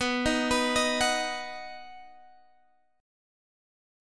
B Chord
Listen to B strummed